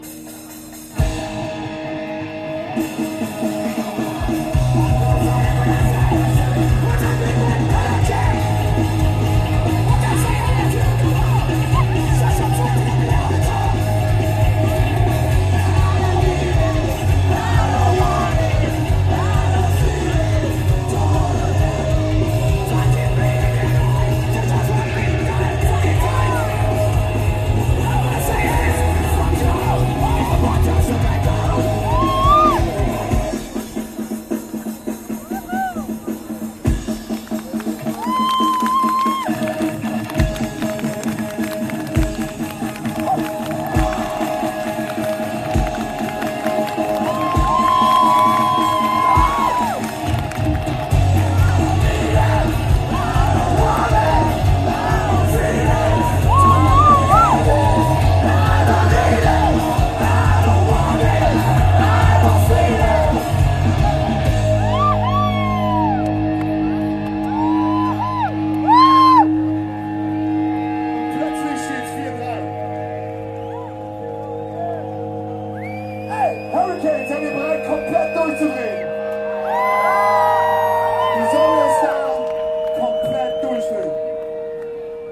Aufnahmegerät: Sharp IM-DR420H (Mono-Modus)
Mikrofon: Sony ECM-T6 (Mono)